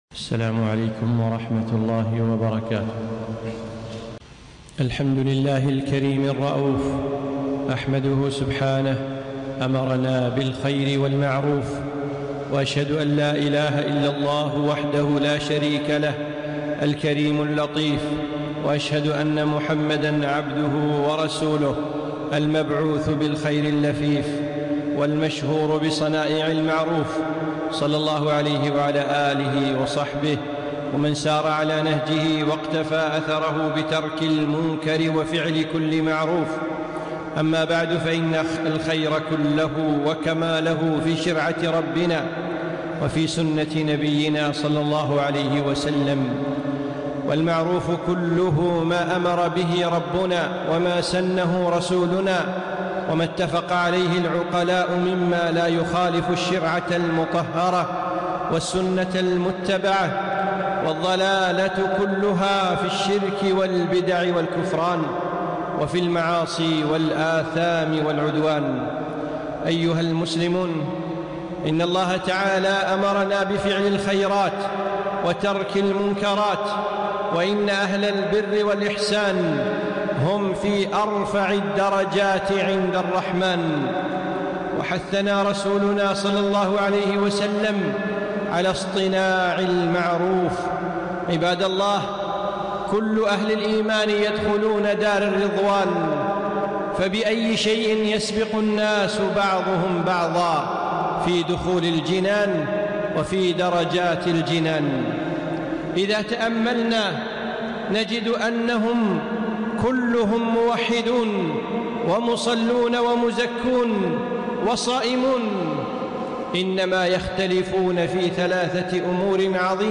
خطبة - صنائع المعروف